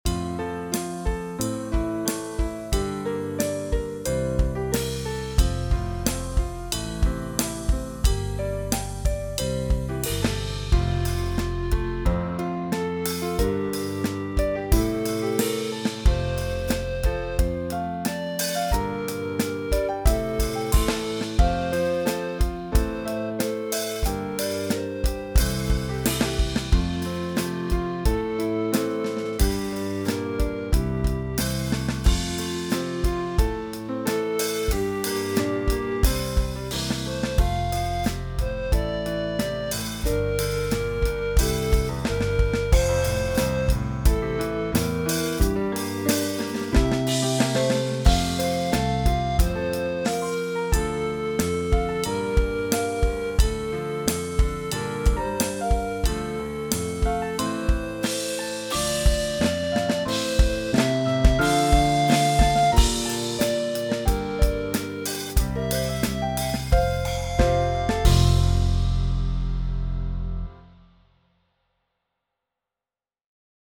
Between February and March 2025 I had finished composing them and proceeded to record the drums parts.
• Vigil (instrumental)